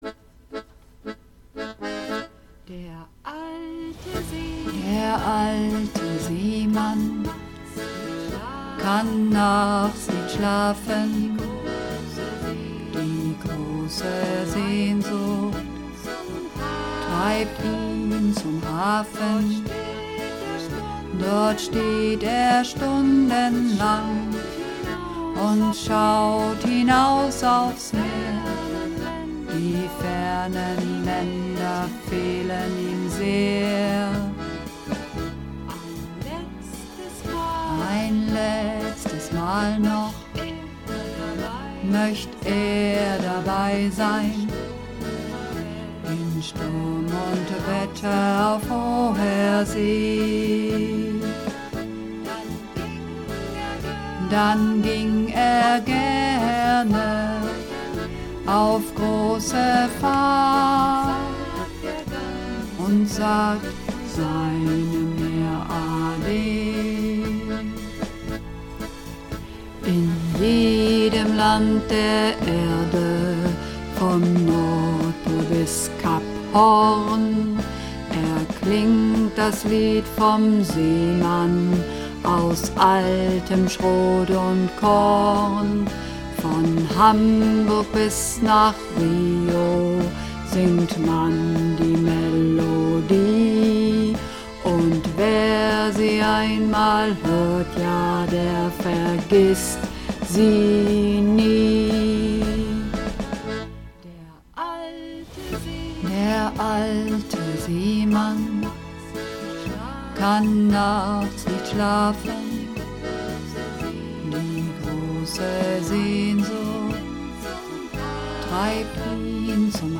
Übungsaufnahmen - Der alte Seemann
Runterladen (Mit rechter Maustaste anklicken, Menübefehl auswählen)   Der alte Seemann (Bass und Männer)
Der_alte_Seemann__2_Bass_und_Maenner.mp3